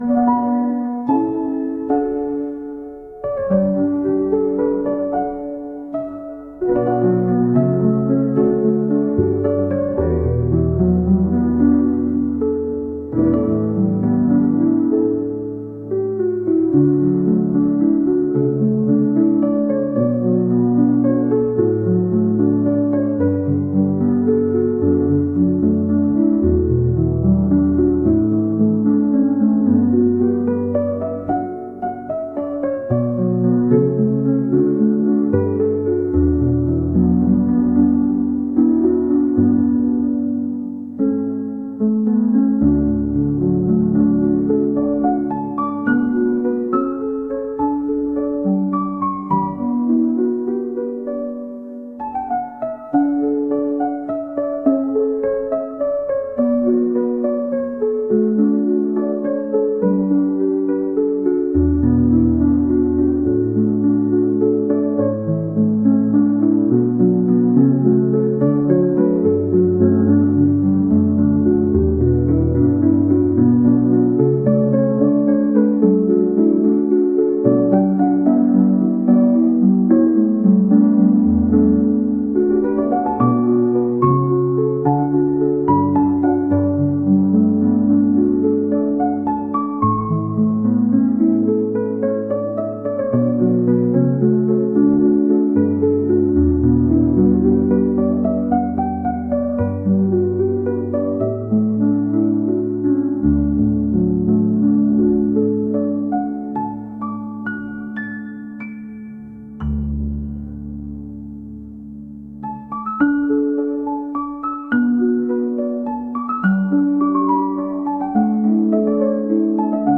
「シリアス」